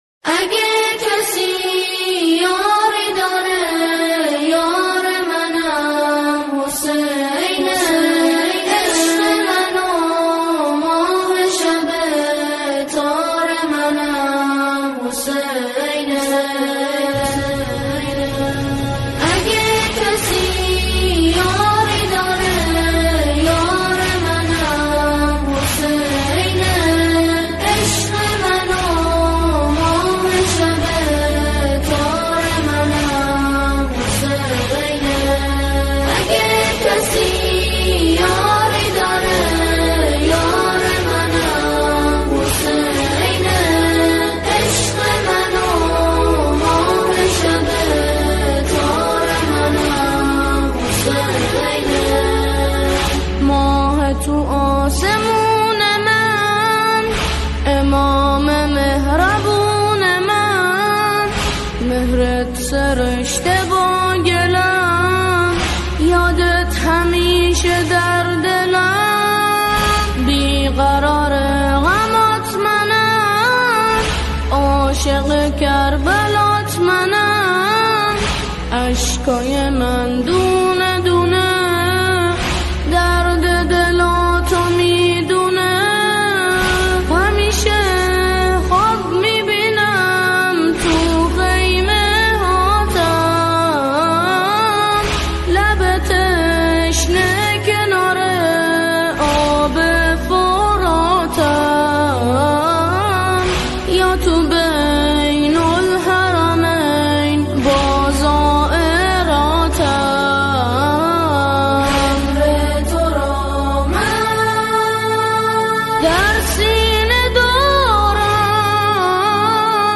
همخوانی کودکانه